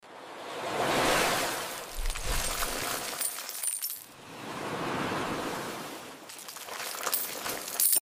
Satisfying ASMR videos of Dollar's